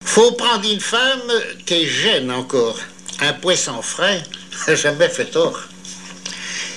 Langue Patois local
Genre dicton
émission La fin de la Rabinaïe sur Alouette